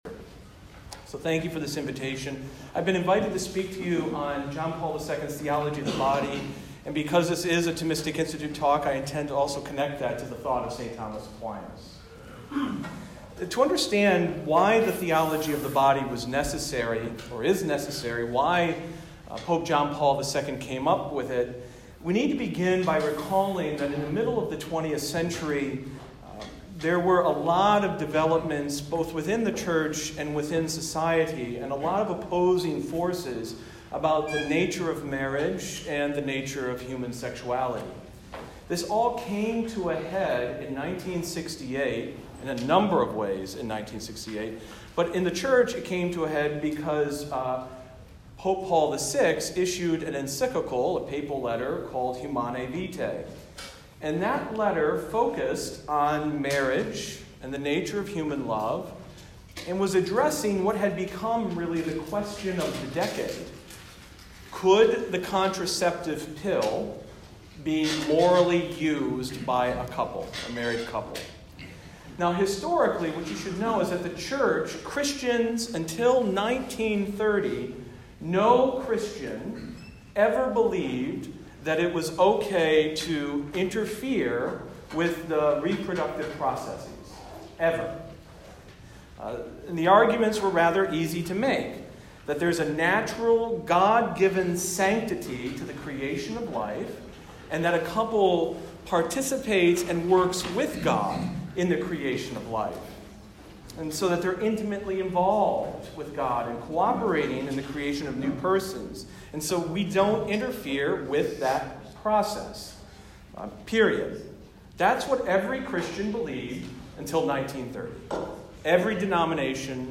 This lecture was given at the US Naval Academy on November 19, 2019.